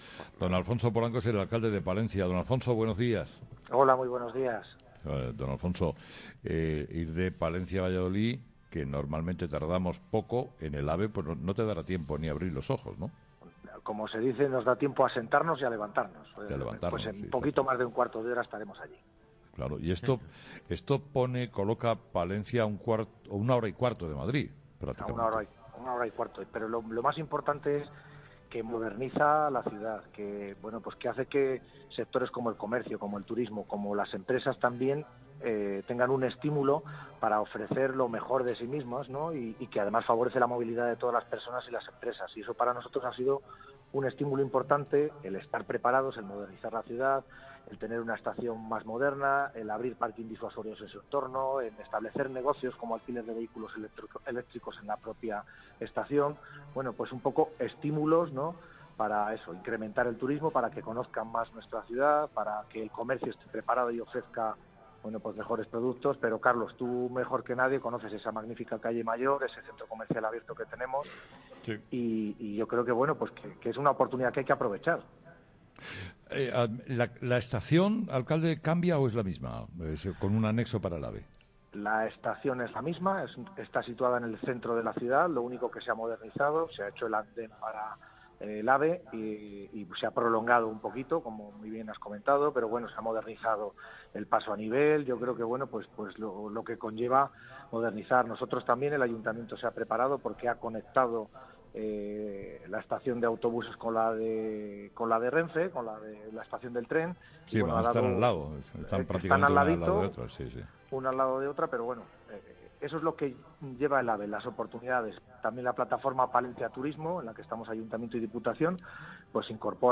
Entrevista a Alfonso Polanco
Alfonso Polanco, alcalde de Palencia,  habla de la llegada del AVE a esta ciudad